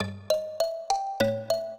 mbira
minuet14-8.wav